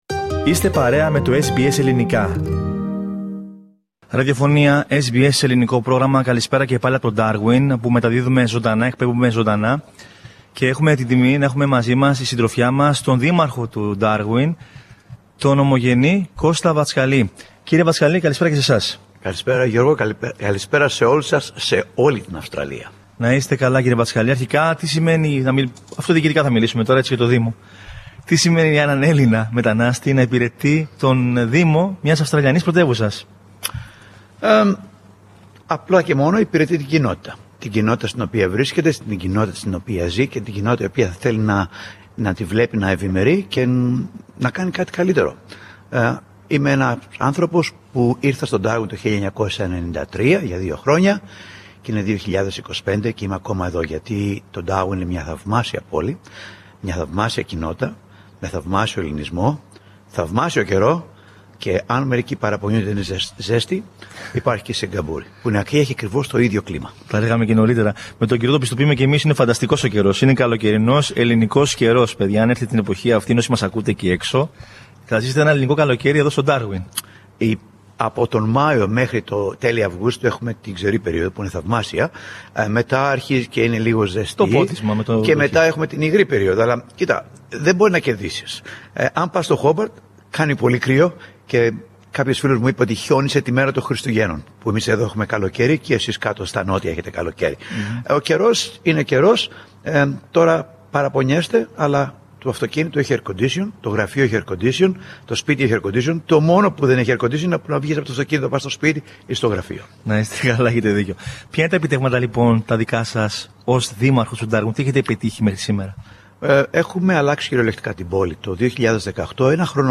Στο πλαίσιο του ταξιδιού μας στο Ντάργουιν για τα 50 χρόνια της Ραδιοφωνίας SBS, συναντήσαμε τον Ελληνοαυστραλό Δήμαρχο της πόλης, Κώστα Βατσκαλή. Με λόγο άμεσο και γεμάτο πάθος, ο κ. Βατσκαλής μίλησε για το προσωπικό του όραμα να μεταμορφώσει το Ντάργουιν σε μια πιο πράσινη, βιώσιμη και εξωστρεφή πόλη, παρά τις προκλήσεις της κλιματικής αλλαγής.